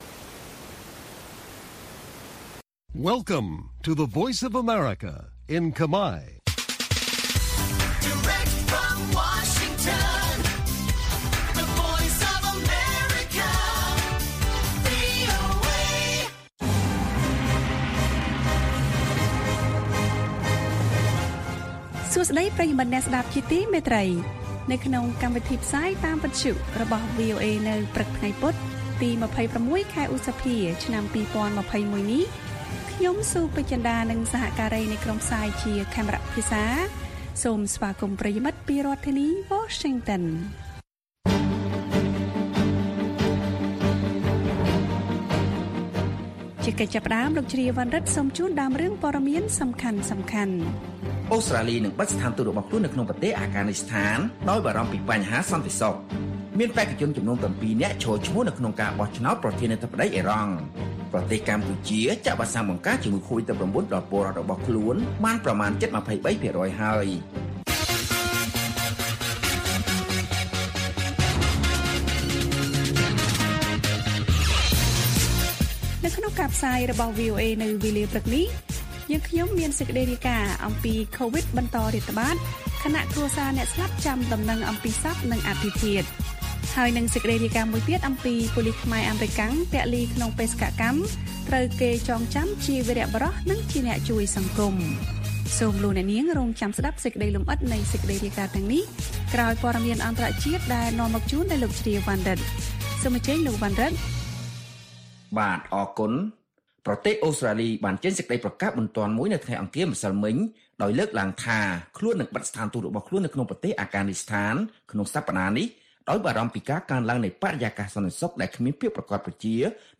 ព័ត៌មានពេលព្រឹក៖ ២៦ ឧសភា ២០២១